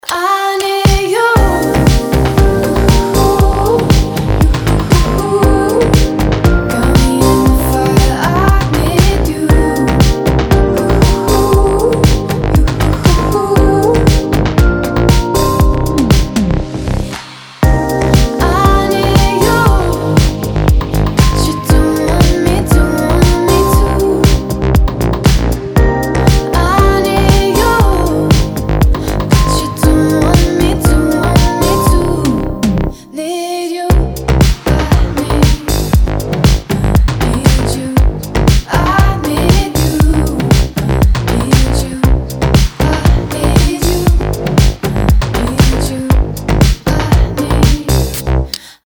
поп
грустные
dance
Electronic
nu disco
Bass